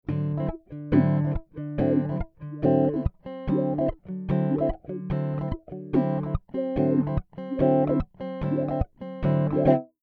Here are some samples of a D9 Chord with the root at the D note on the 5th fret.
D9 Chord Sound Sample 3
D9th Funk Chord
d9thwah.mp3